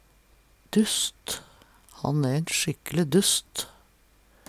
dusst - Numedalsmål (en-US)